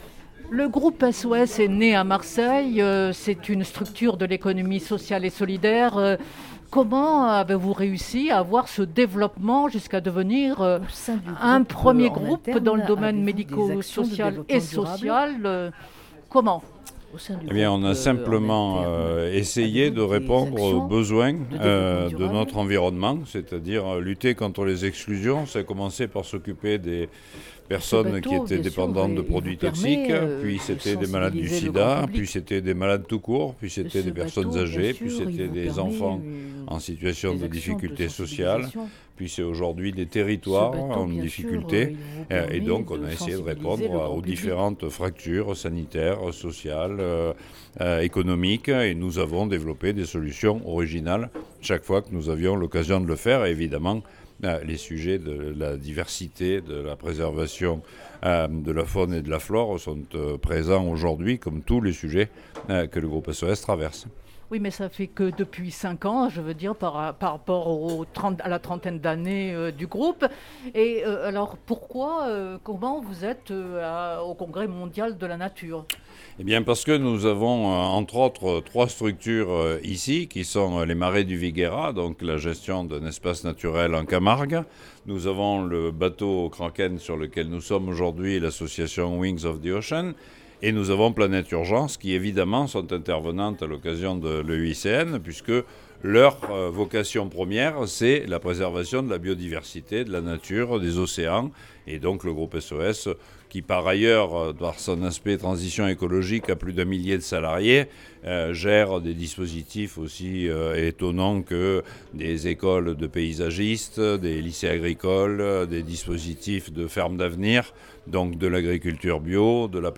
Groupe qui est surtout connu pour ses actions dans le social et le médico-social. Entretien.